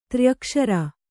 ♪ tryakṣara